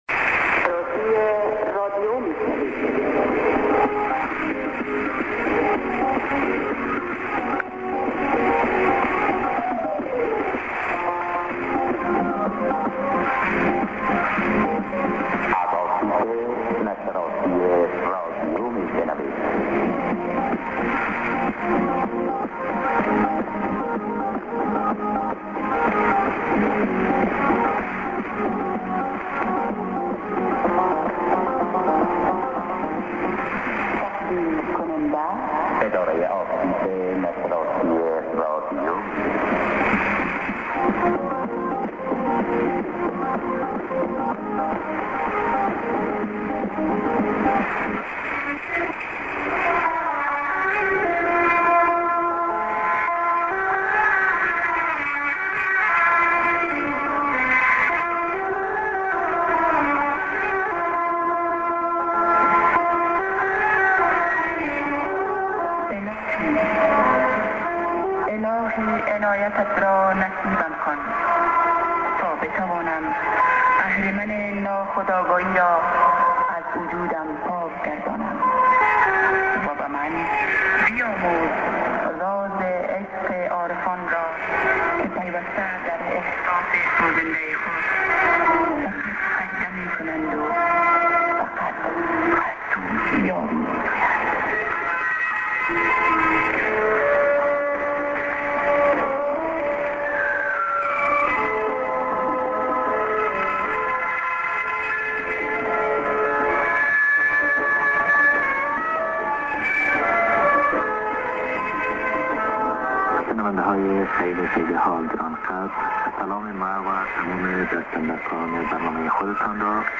St. ID(man)->music+ID(women+man)->music